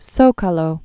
(sōkä-lō)